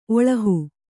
♪ oḷahu